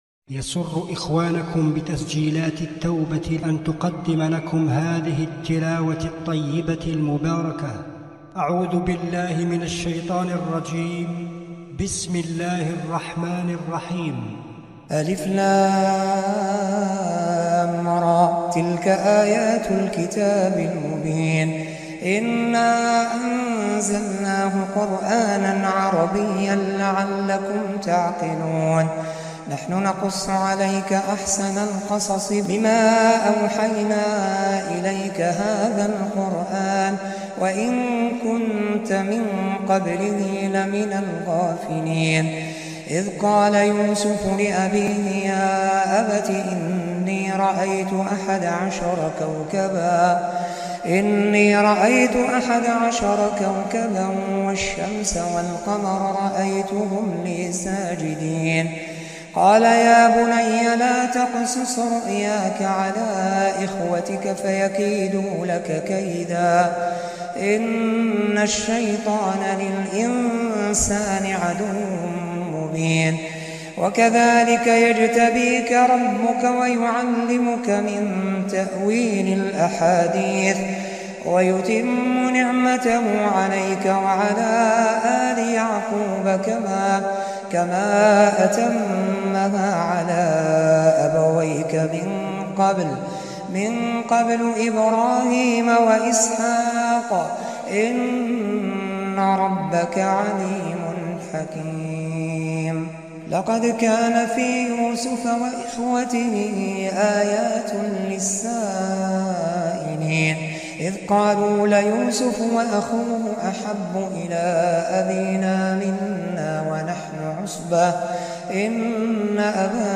تلاوة خاشعة